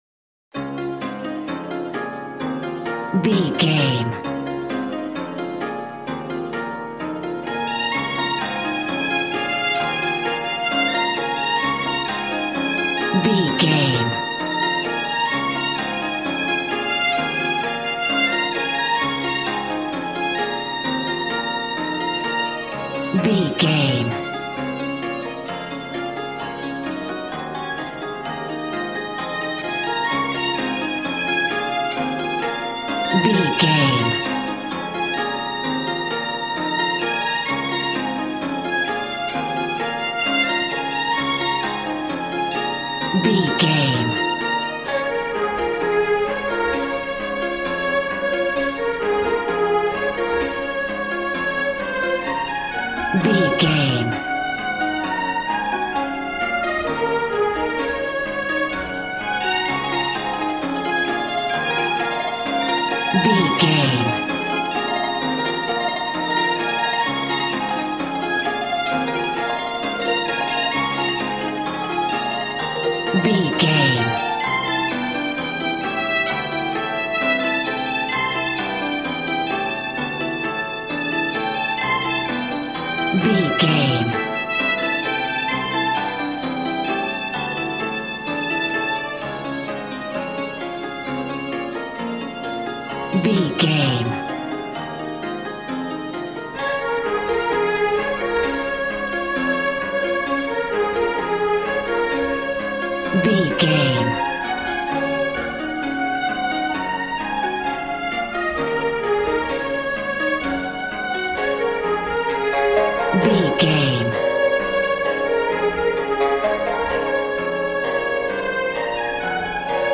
Aeolian/Minor
B♭
smooth
conga
drums